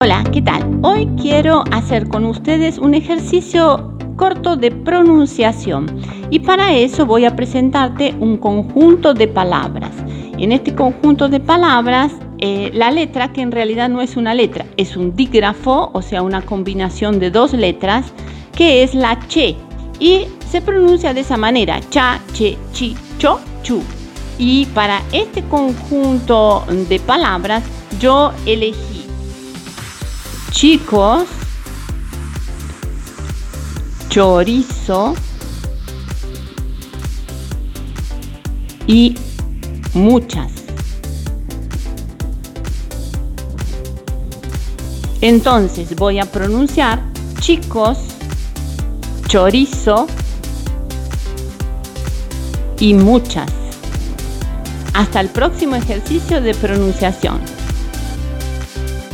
En este podcast estamos practicando la pronunciación del dígrafo CH.
También conocido como CHE /ch/.
tip-de-pronunciacion-1.mp3